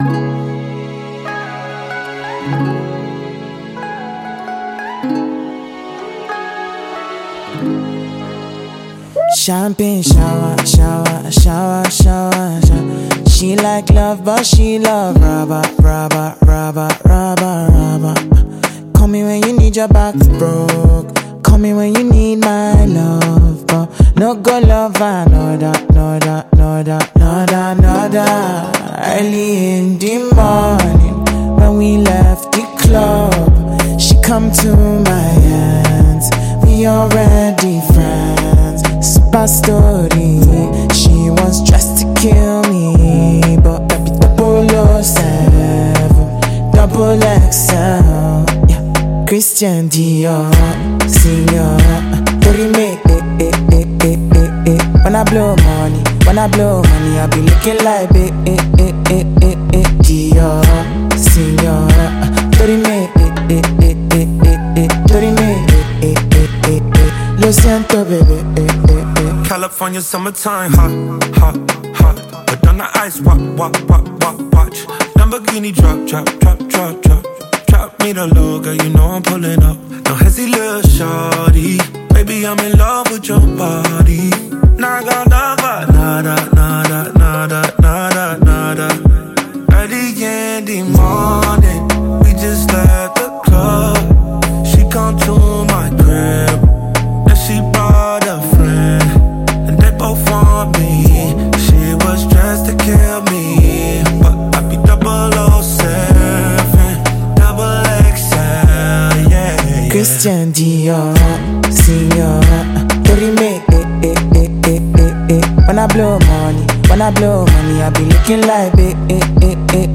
Nigerian Music
Afrobeats